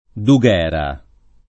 [ du g$ ra ]